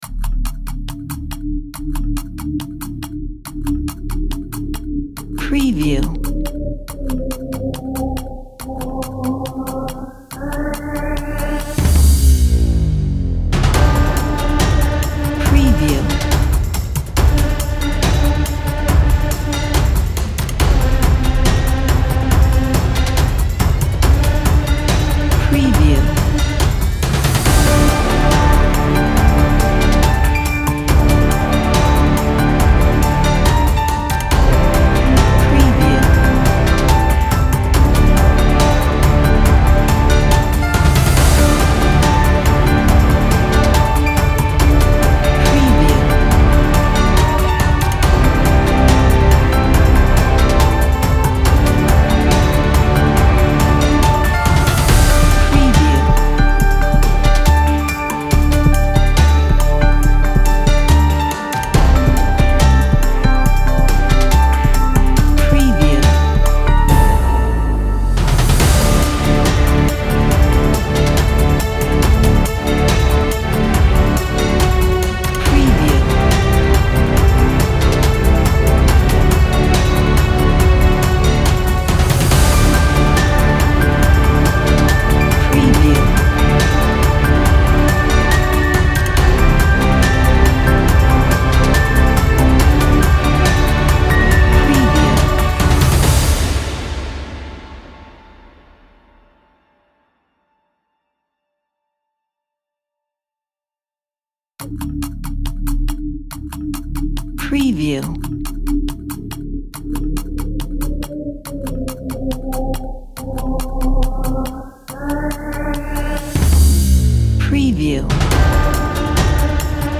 royalty free epic action adventure music
Mood: epic, huge, powerful, adventure, action.
Tempo: 140 BPM
Key: D minor